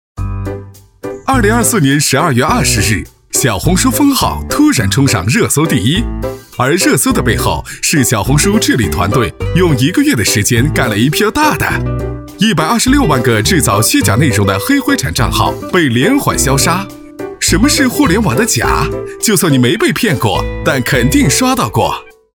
男国语217